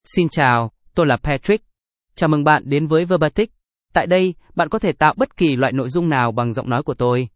MaleVietnamese (Vietnam)
PatrickMale Vietnamese AI voice
Voice sample
Patrick delivers clear pronunciation with authentic Vietnam Vietnamese intonation, making your content sound professionally produced.